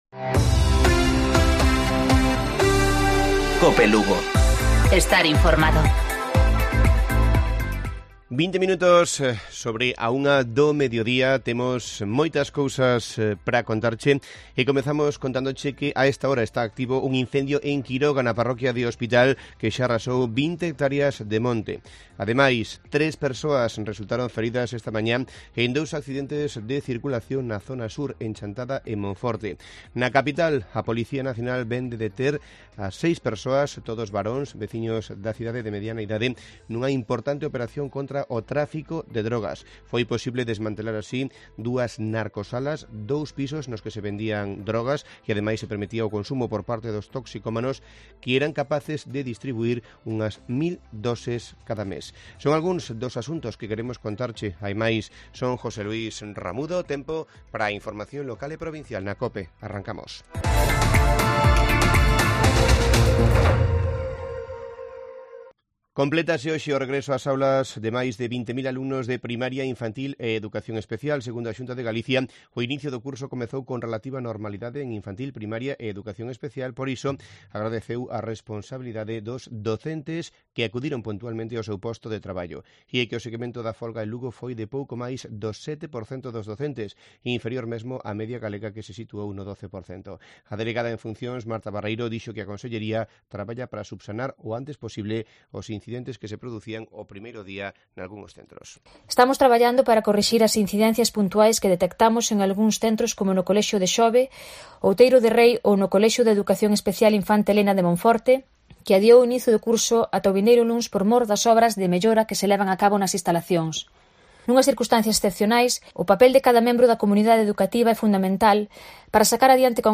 Informativo Provincial de Cope Lugo. 11 de septiembre. 13:20 horas